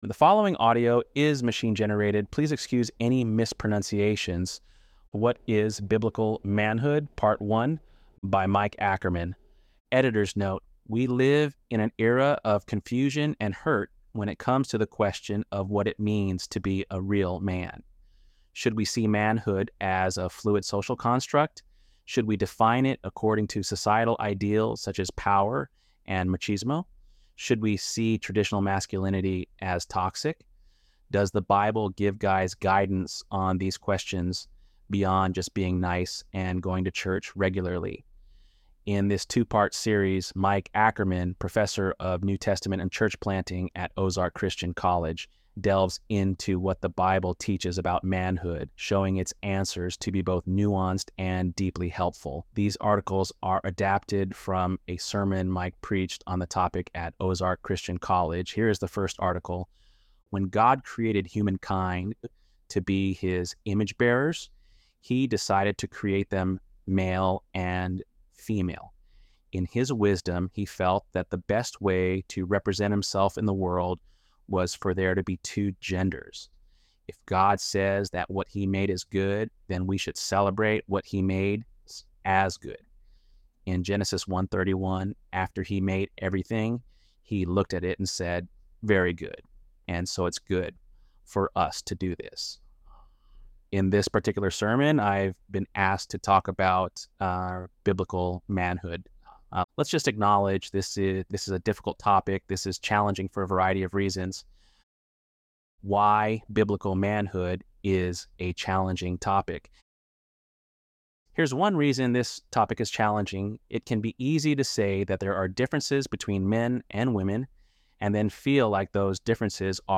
ElevenLabs_Untitled_Project-3.mp3